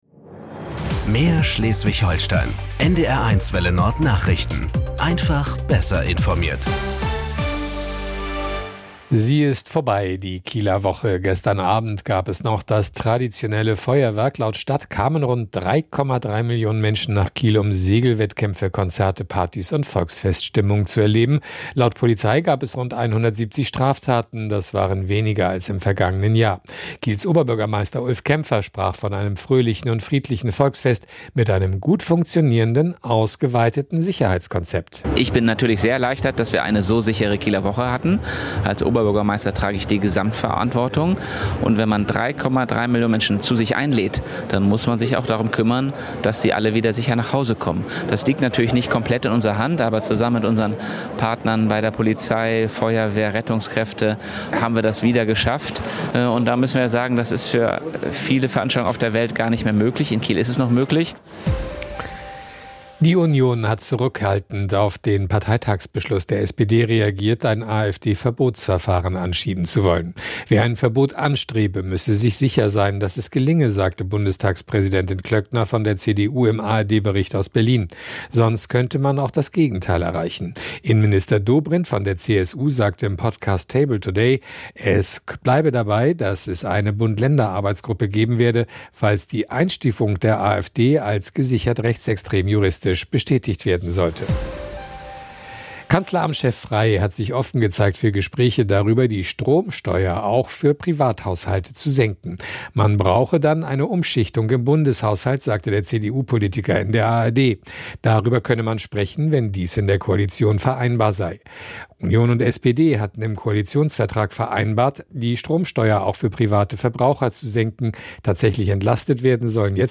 Nachrichten 12:00 Uhr - 30.06.2025